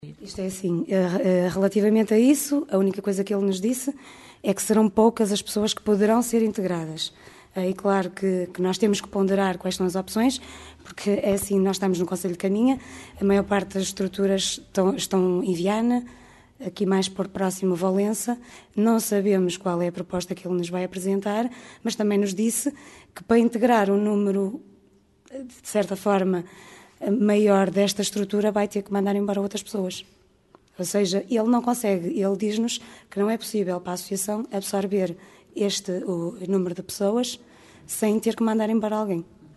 Em declarações no final da reunião com a direção